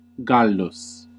Ääntäminen
Synonyymit cuisinier louis huppe apogon Ääntäminen France: IPA: [ɛ̃ kɔk] Tuntematon aksentti: IPA: /kɔk/ Haettu sana löytyi näillä lähdekielillä: ranska Käännös Ääninäyte 1. gallus {m} Suku: m .